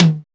RX TOM HI.wav